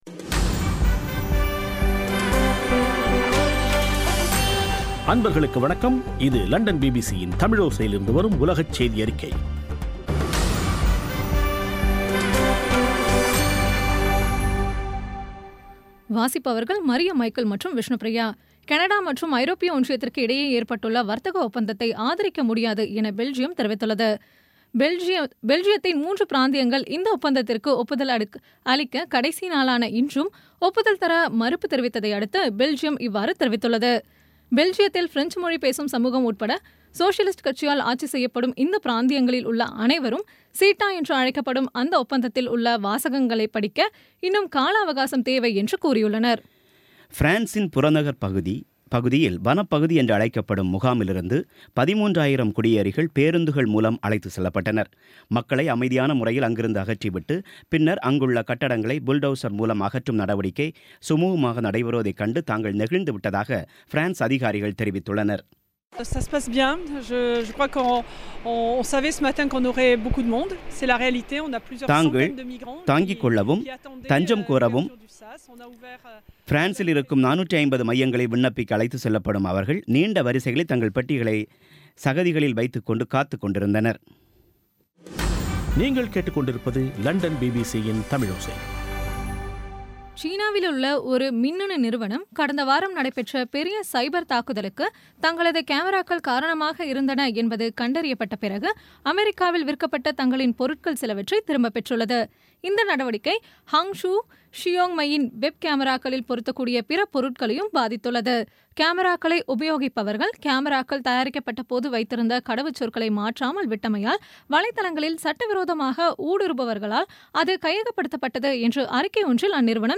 இன்றைய (அக்டோபர் 24-ஆம் தேதி) பிபிசி தமிழோசை செய்தியறிக்கை